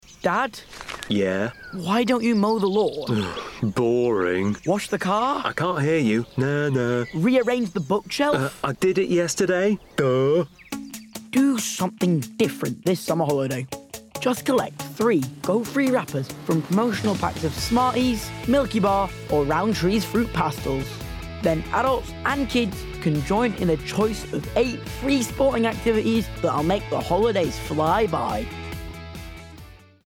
14-16 | Bright & Clear
Voice reel